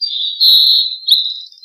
由JCG Musics于2015年创建的名为AmbiGen的装置中使用的单个鸟啁啾和短语。
Tag: 鸟鸣声 森林 性质 现场录音